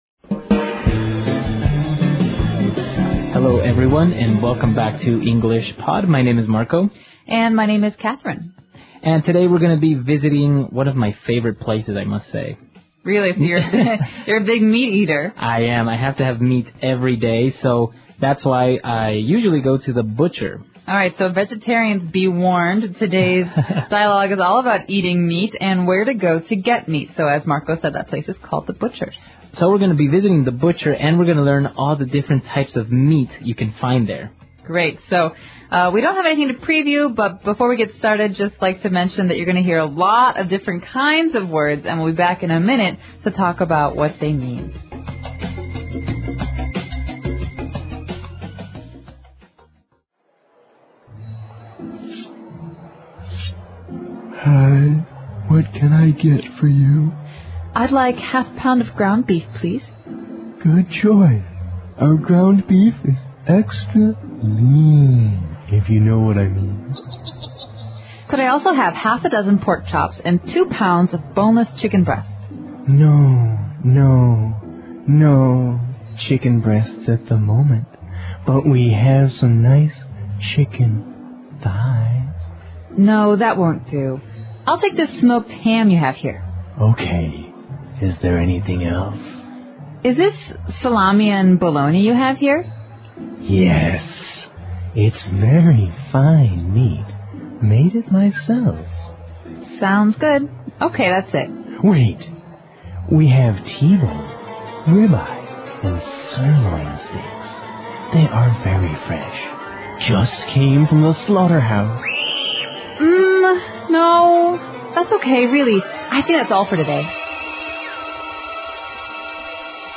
纯正地道美语 第142期(外教讲解):去市场买肉 听力文件下载—在线英语听力室